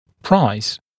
[praɪz][прайз]рычаг, действие рычага; поднимать, передвигать или взламывать посредством рычага